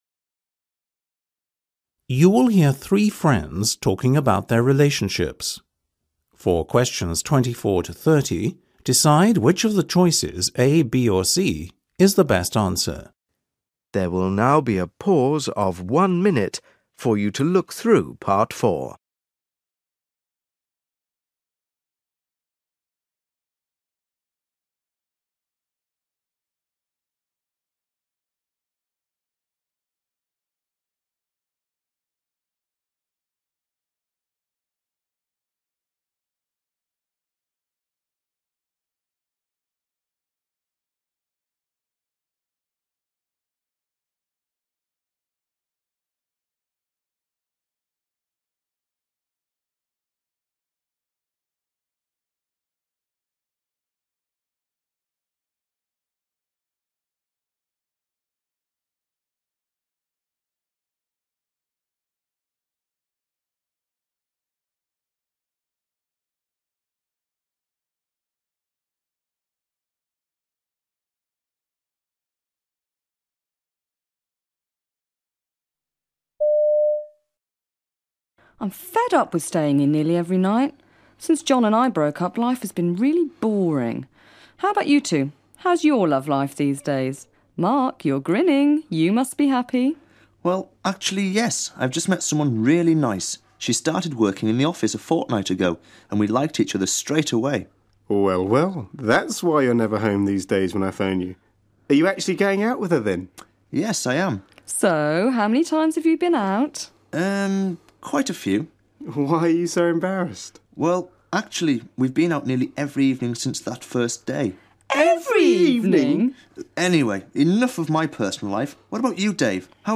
You will hear three friends talking about their relationships.